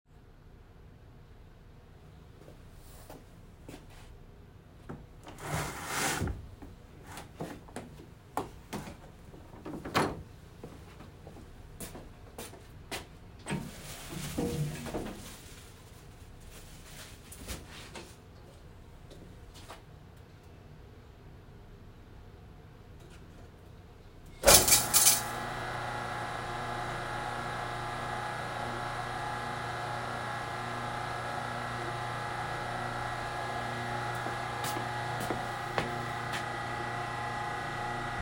Field recording #2
This was recorded at the ceramic studio – you can hear the hum of the kiln room fan in the background, a bucket being slid across the floor, my hand hitting the doorknob, my footsteps towards our clay reclaim machine, the sounds of the plastic cover being taken off, the sound of the machine being turned on, and then finally my footsteps back to my phone.
Ceramic-Studio-sounds.m4a